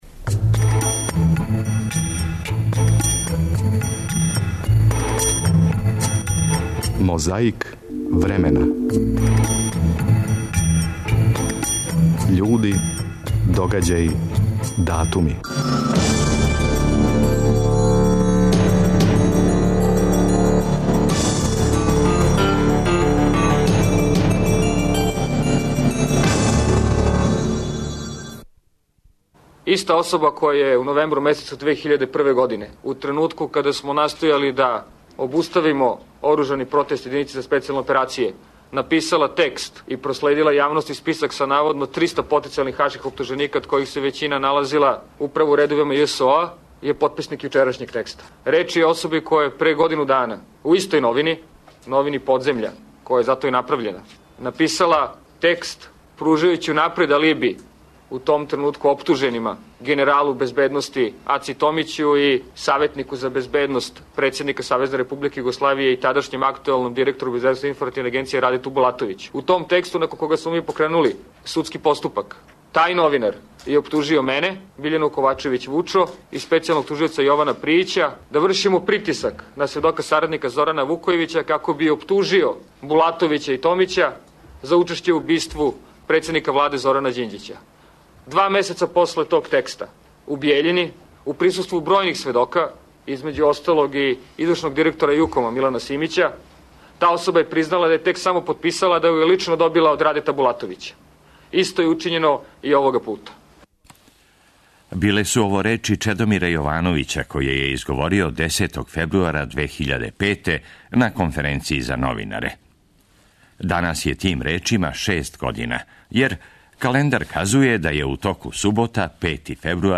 Чућете шта је том приликом говорио Зоран Лилић.
Чућете како је и зашто Јанез Дрновшек, као председник Председништва тадашње државе, у уводном излагању говорио двојезично.
Подсећа на прошлост (културну, историјску, политичку, спортску и сваку другу) уз помоћ материјала из Тонског архива, Документације и библиотеке Радио Београда.